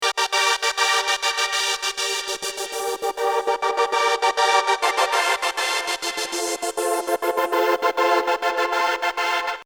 To gate a synth means to frequently modulate its volume in quick successions.
Lets listen to a cool gated synth from Sylenth1.
synth_gate_audio_ex_1.mp3